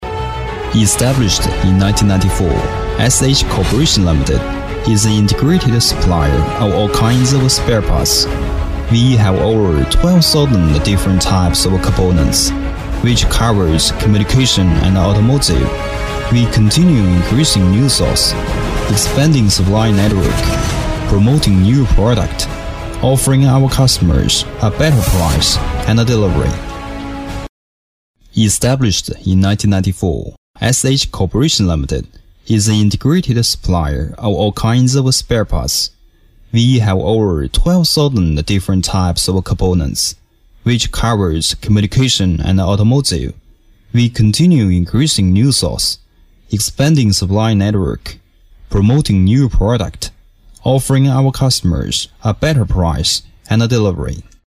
男47号配音师
It is energetic, vigorous, firm and passionate.
英文-专题片-男47-English topic introduction .mp3